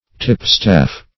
Tipstaff \Tip"staff`\, n.; pl. Tipstaff.